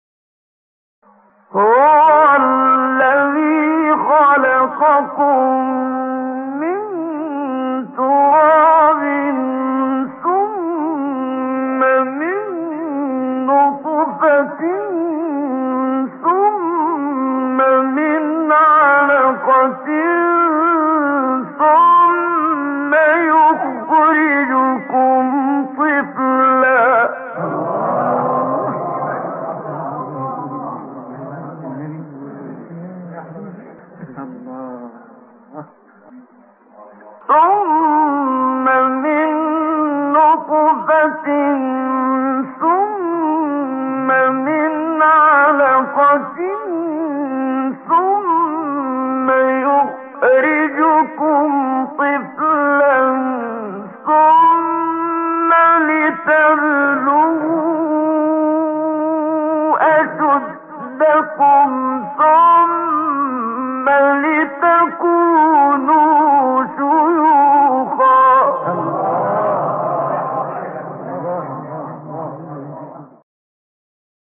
فایل‌های تلاوت تقلیدی
قطعه تلاوت تقلیدی استاد منشاوی